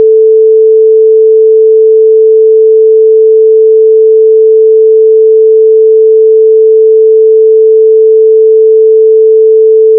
wav, 16 bits, 11025 sampling rate, stereo, 440 Hz, 10 seconds